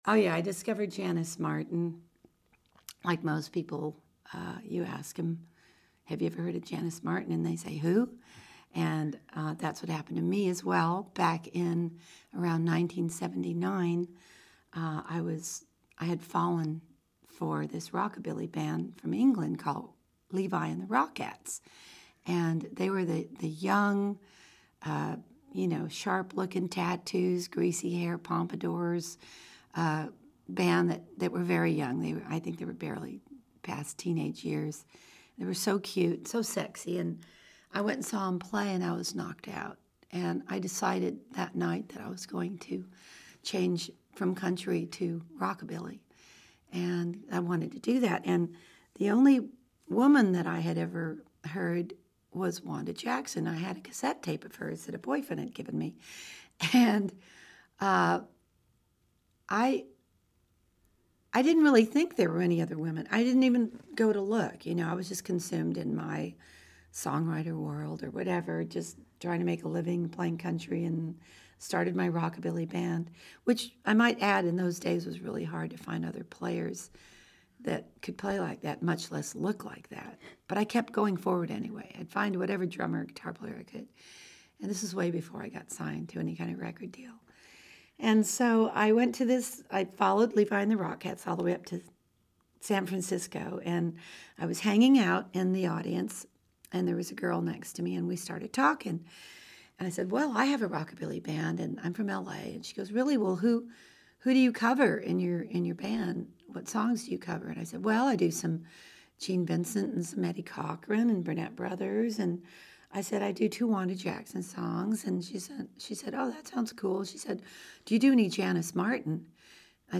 Interview Archive Browse and search interviews with and about the first women rock and rollers. Rosie Flores on Janis Martin Dublin Core Title Rosie Flores on Janis Martin Subject Janis Martin Description Singer, songwriter, and producer Rosie Flores talks about discovering and working with rockabilly singer Janis Martin .